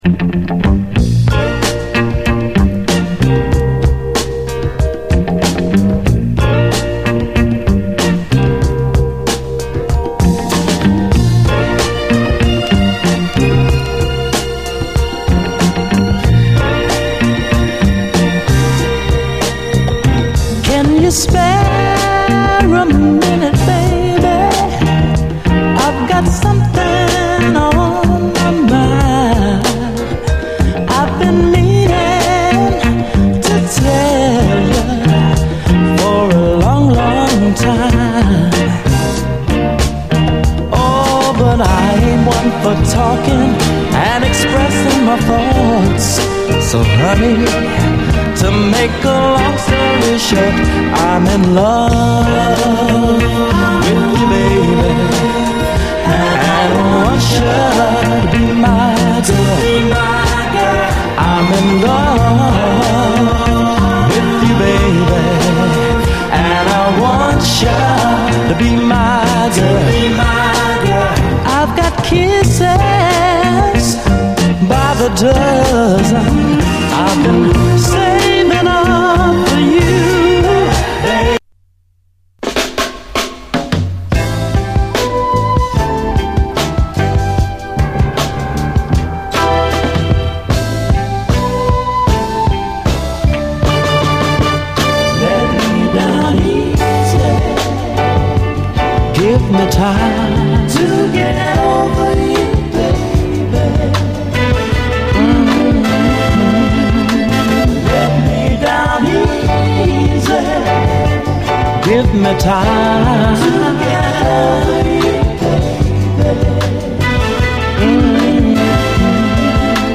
SOUL, 70's～ SOUL
ディスコではなく、黄金のスウィート・クロスオーヴァー・ソウル期のサウンド！ドリーミー・スウィート・ソウル！